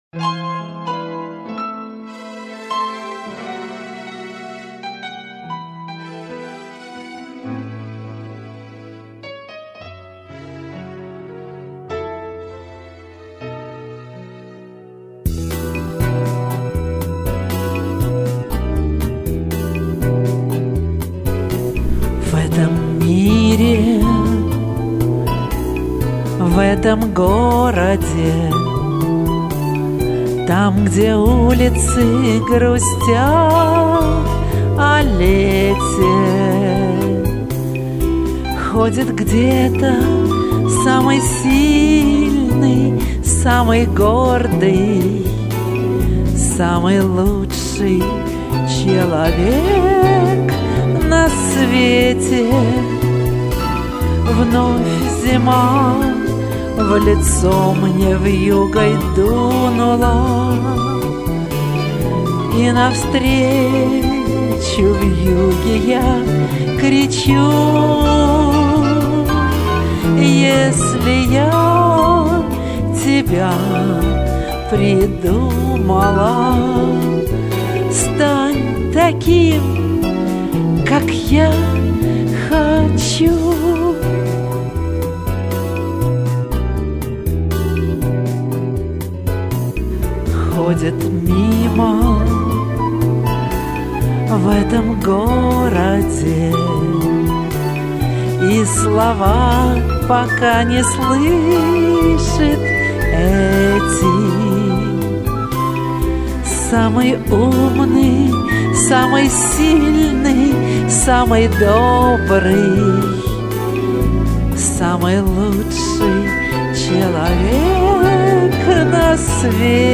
Пришлось чуть поднять.